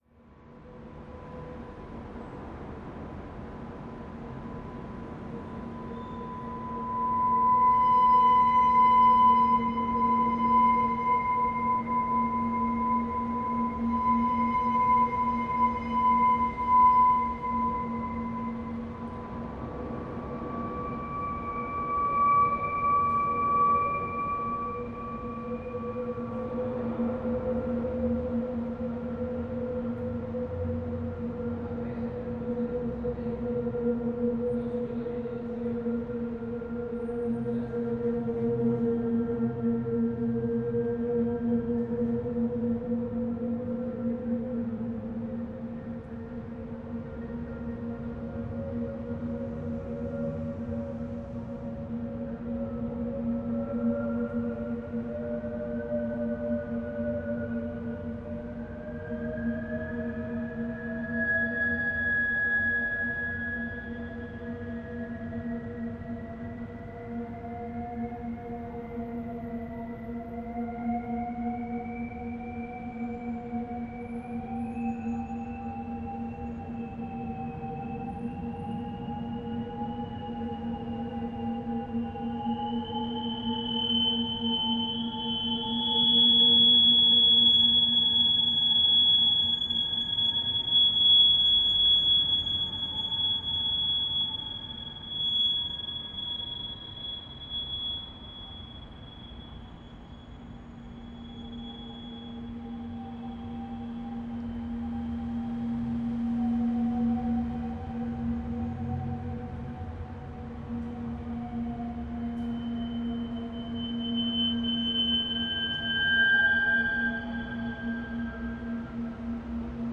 A 50-meter long public underpass is transformed into a sonic chamber using controlled feedback.
Feedback loops were controlled individually with a computer to avoid infinite loudness growth, and to transform their sound, creating some sort of electronic feedback choir in perpetual state of instability.
The characteristics of the site -having both ends open to outdoor urban spaces- made it possible for the system to react to ambient sound in an organic way, incorporating the sound of wind, voices, traffic, planes, birds, etc. to the sonic environment of the tunnel.
Location recording of the installation. Traveling shot from the west entrance to the middle of the tunnel